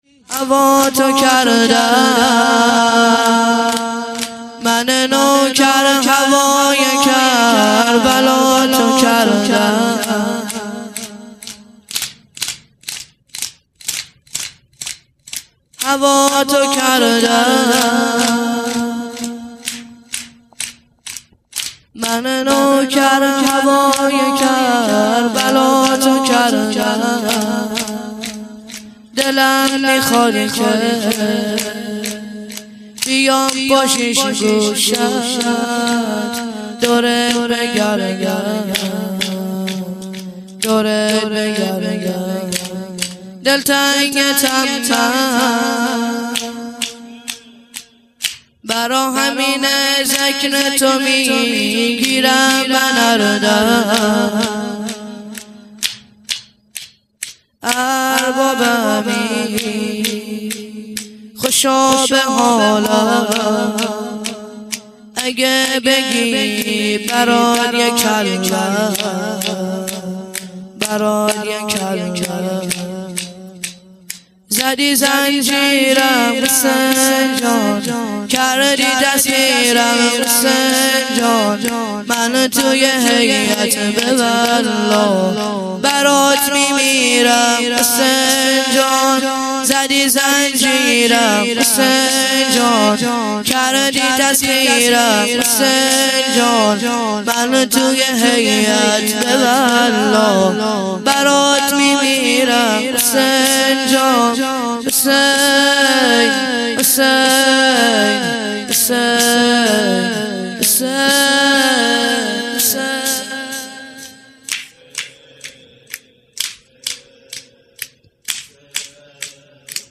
چهار ضرب - هوا تو کردم